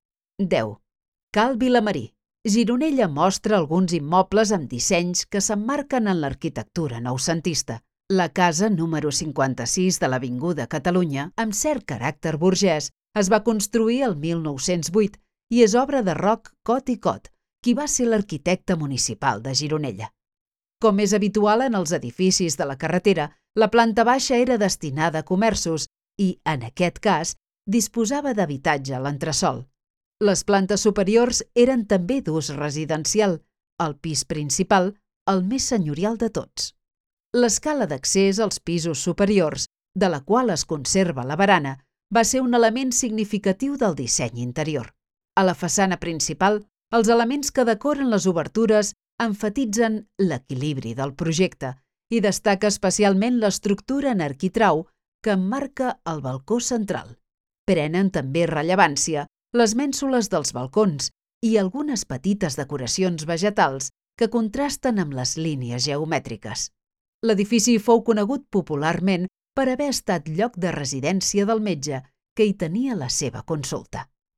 Audioguia